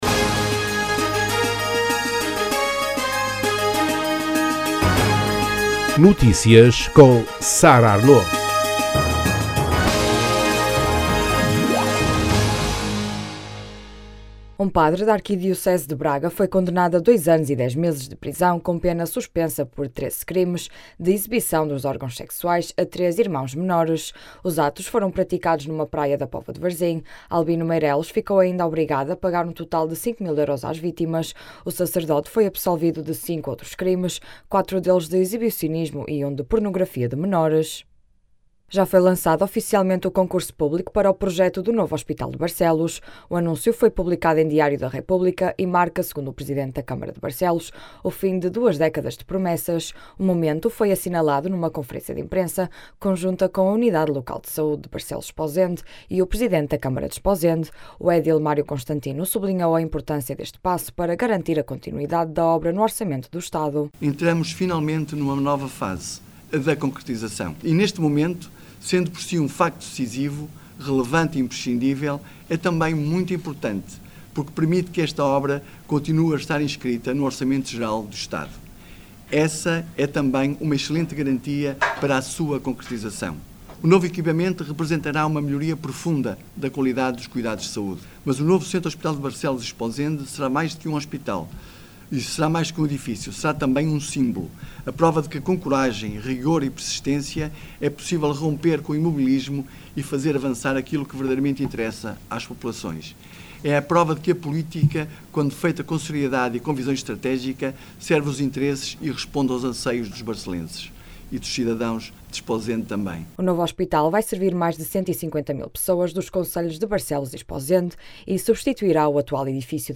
O momento foi assinalado numa conferência de imprensa conjunta com a Unidade Local de Saúde de Barcelos/Esposende e o presidente da câmara de Esposende.
As declarações podem ser ouvidas na edição local.